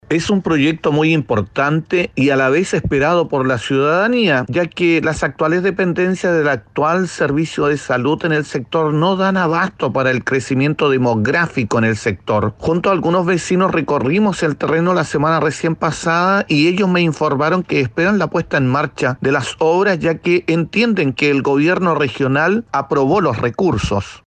Por su parte el Concejal Sandro Puebla se refirió a las actuales dependencias de asistencia de salud en Viña del Mar las cuales, según sus palabras, no dan abasto.